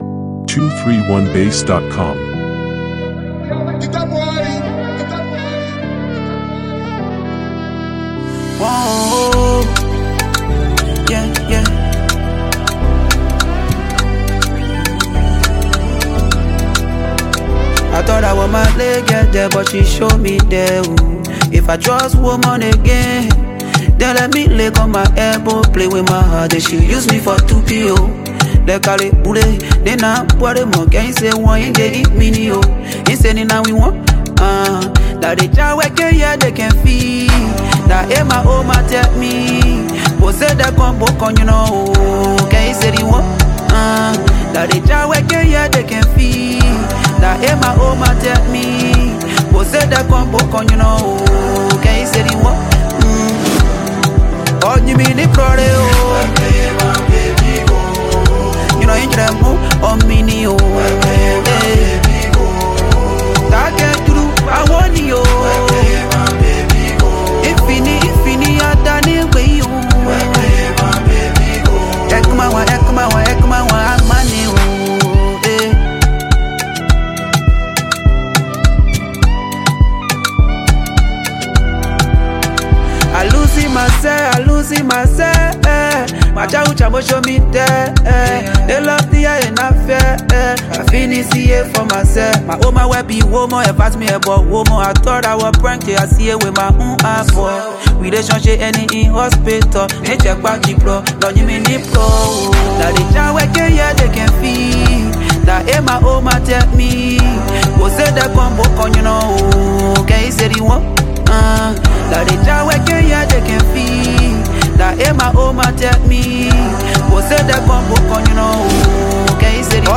With its stripped-back production